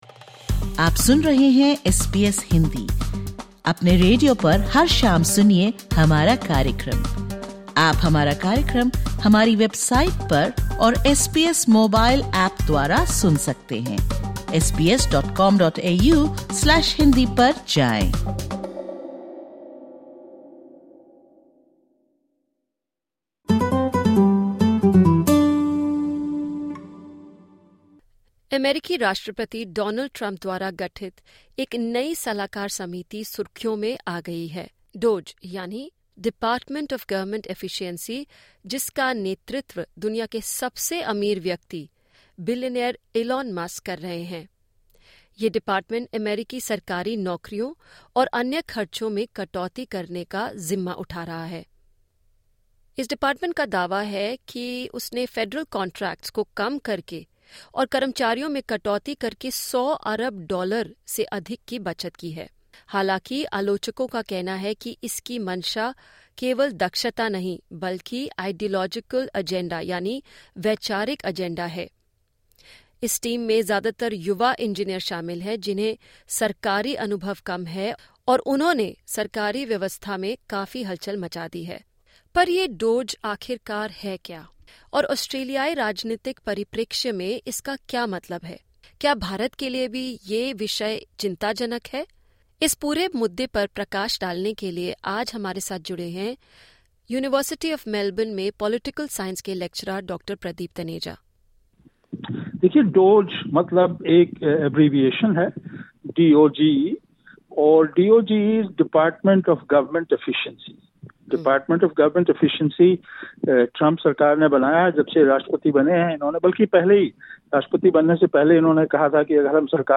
The views/opinions expressed in this interview are the personal views of the individual.